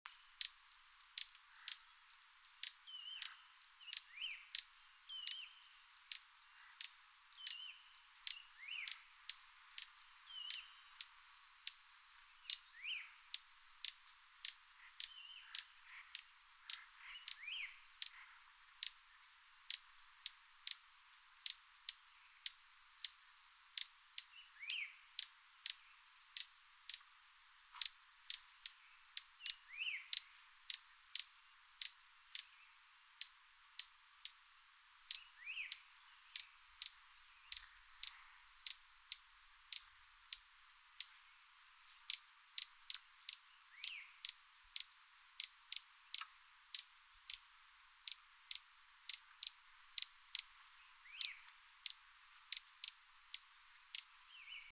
30-2塔塔加2012mar26深山鶯叫1.mp3
黃腹樹鶯 Cettia acanthizoides concolor
南投縣 信義鄉 塔塔加
錄音環境 森林
鳥叫
Sennheiser 型號 ME 67